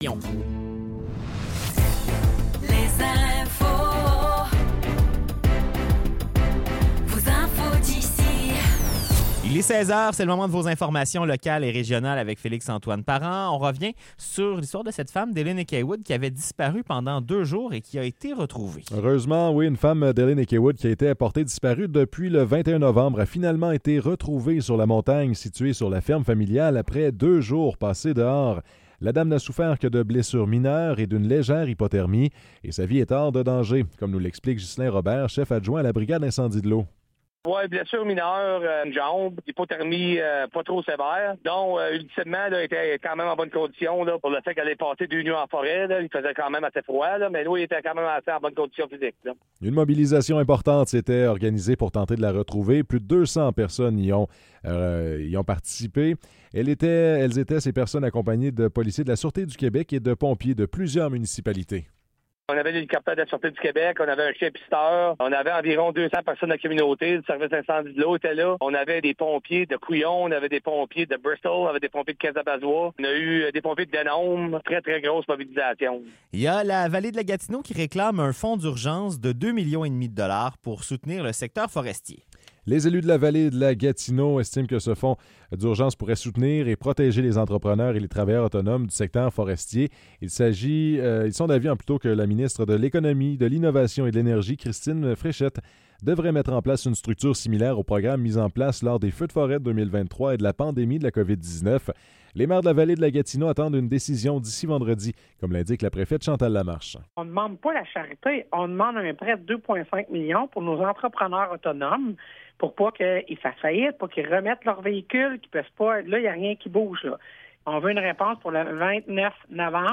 Nouvelles locales - 26 novembre 2024 - 16 h